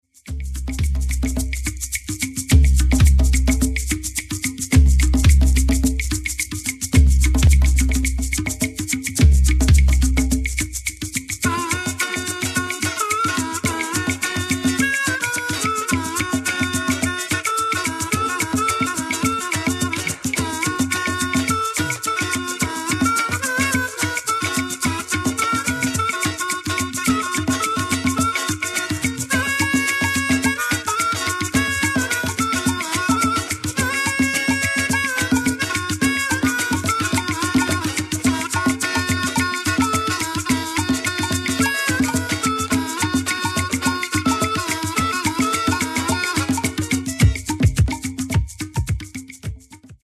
Genere:    Disco | Electro Funky